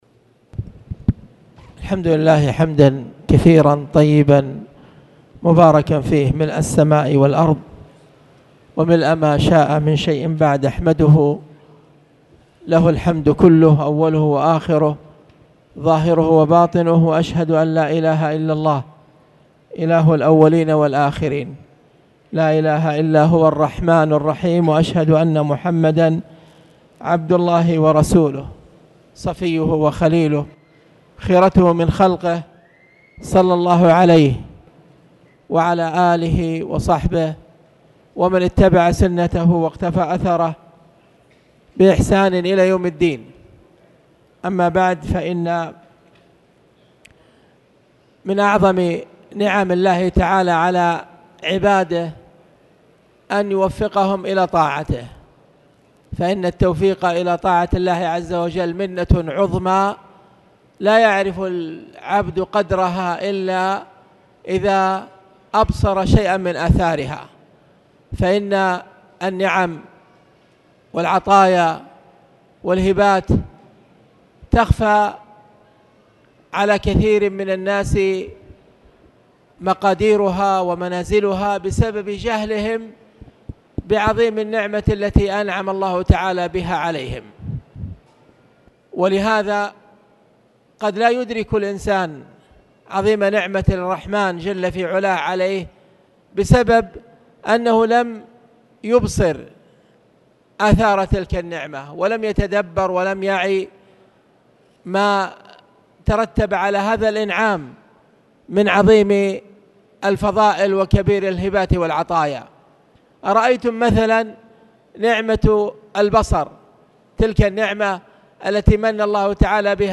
تاريخ النشر ٢ ربيع الأول ١٤٣٨ هـ المكان: المسجد الحرام الشيخ